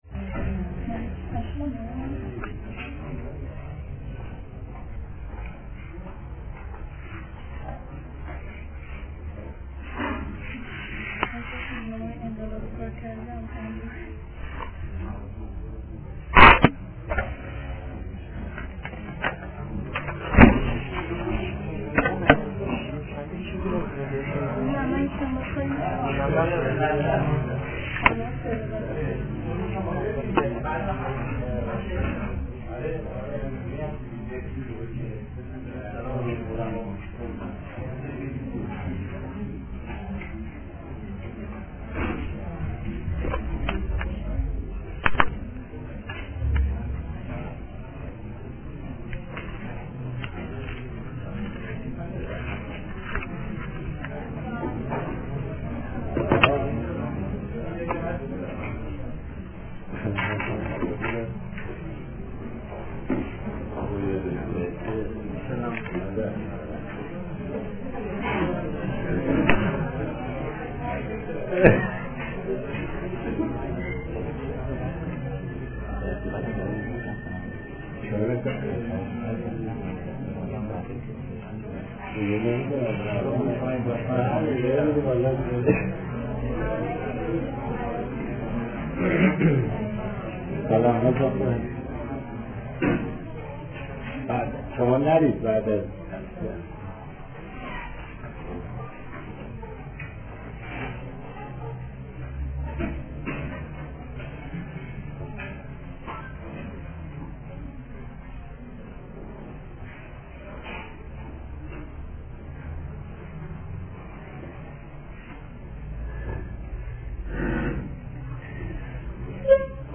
صوت/درس‌گفتارهای ماکیاوللی(۱)
فرهنگ امروز: فایل حاضر قسمت اول درس‌گفتار های ماکیاوللی است که «سیدجواد طباطبایی» سال‌ها پیش آن را تدریس کرده است.